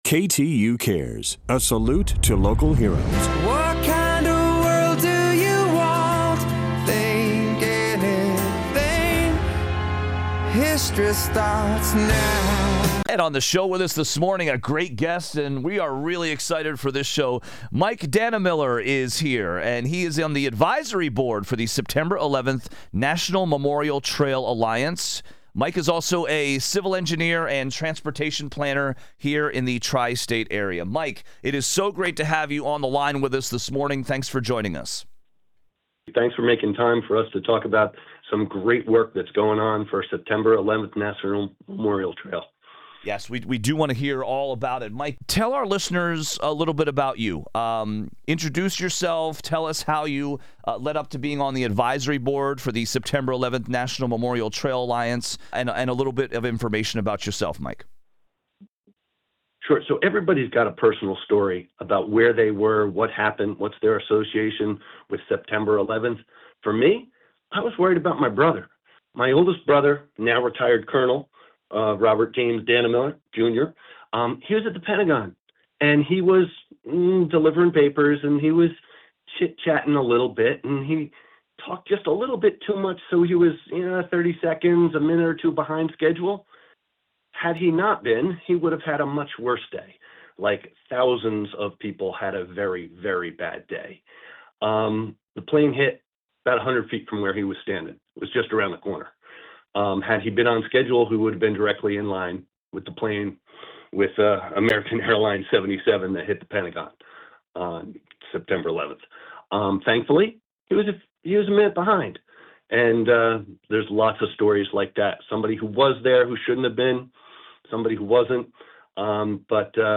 wide-ranging interview broadcast across two major NYC iHeart Media stations